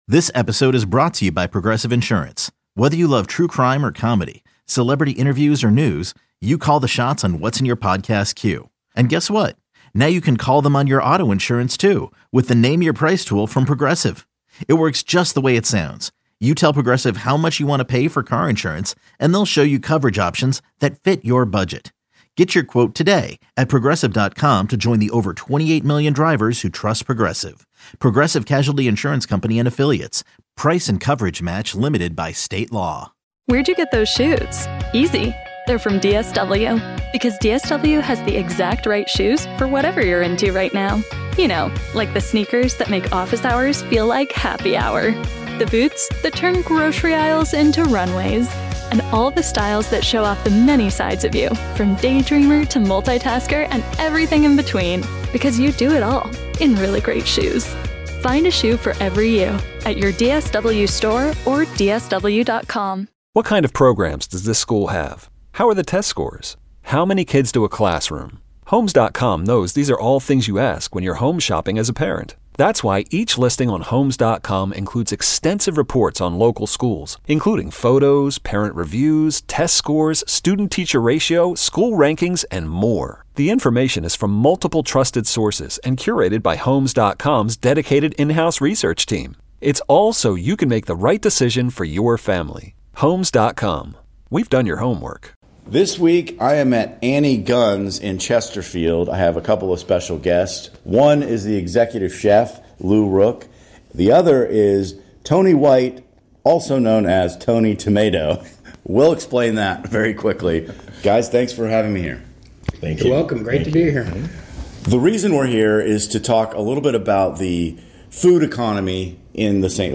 KMOX 98.7 FM Newsradio 1120 Interview